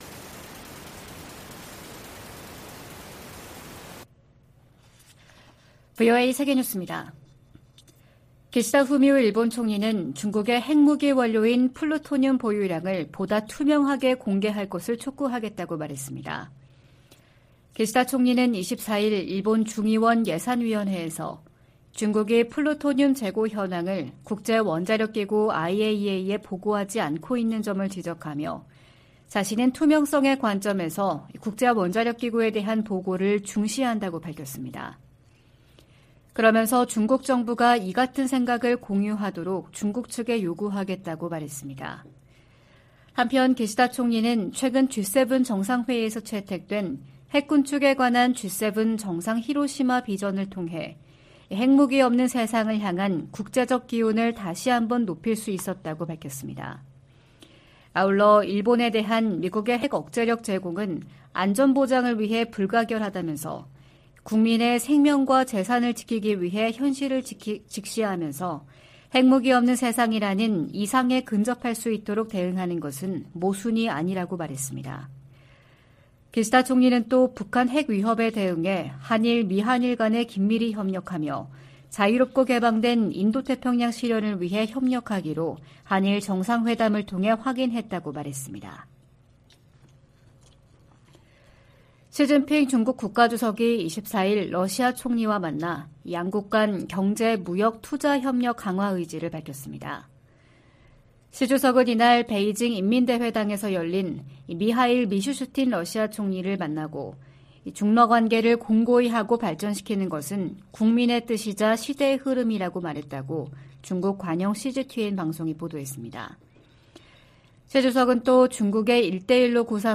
VOA 한국어 '출발 뉴스 쇼', 2023년 5월 25일 방송입니다. 미 재무부가 북한의 불법 무기 프로그램 개발에 필요한 자금 조달과 사이버 활동에 관여한 기관 4곳과 개인 1명을 제재했습니다. 북한이 27년 연속 미국의 테러 방지 노력에 협조하지 않는 나라로 지정됐습니다. 북한 핵역량 고도화로 한국에서 자체 핵무장론까지 나온 상황은 워싱턴에 경종을 울린다고 전직 백악관 고위 관리들이 말했습니다.